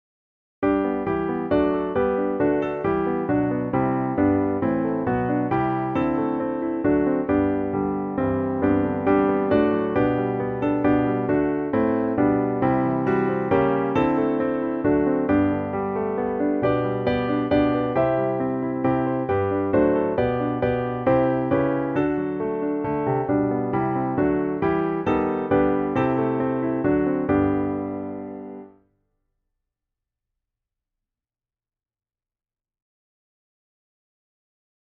Writer: Joachim Neander (1650-1680)
Music: From Praxis Pieatis Melica (1668)
hymn-praise-to-the-lord-the-almighty.mp3